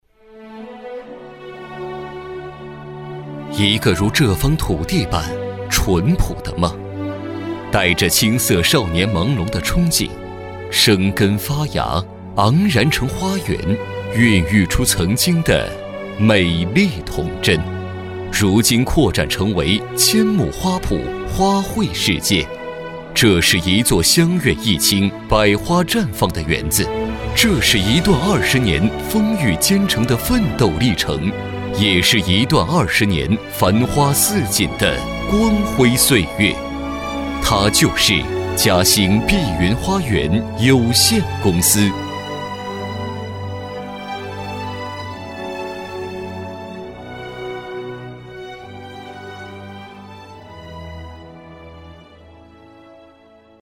C男158号
【专题】大气深情
【专题】大气深情.mp3